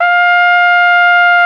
Index of /90_sSampleCDs/Roland L-CD702/VOL-2/BRS_Flugelhorn/BRS_Flugelhorn 1